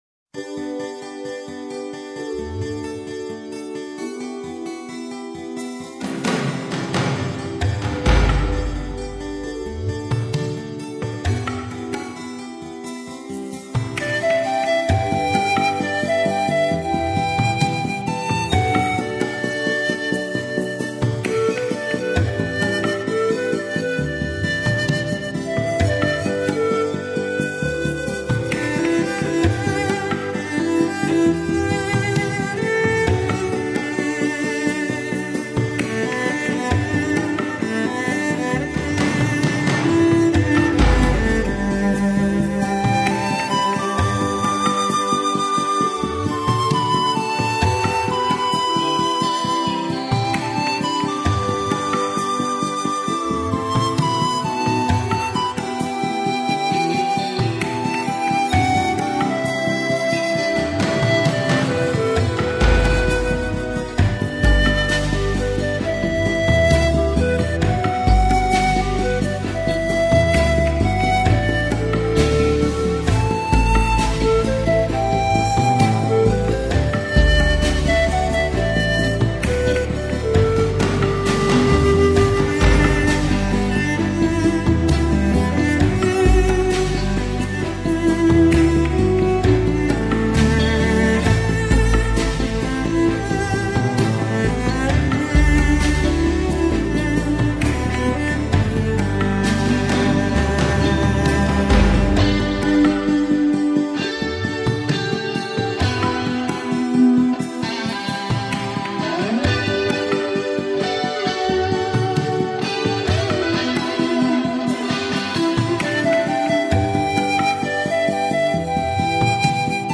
[2005-7-4]大提琴与排箫——流淌